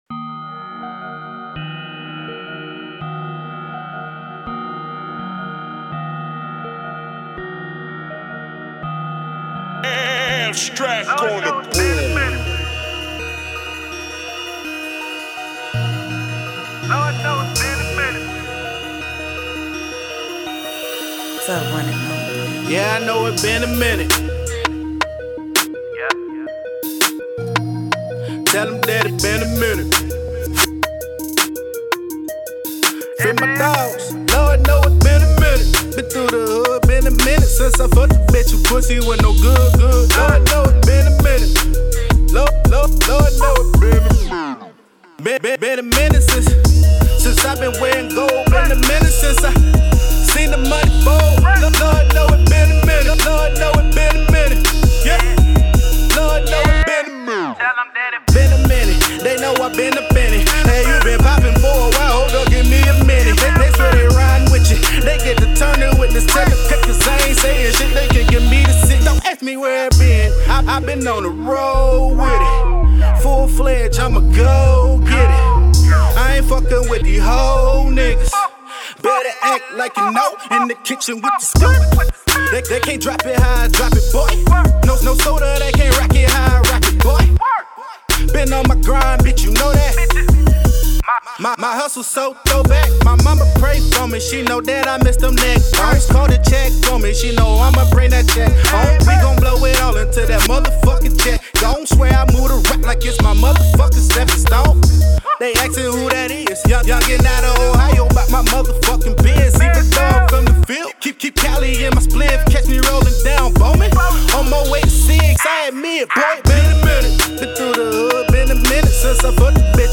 Hiphop
THIS SONG IS MOTIVATIONAL.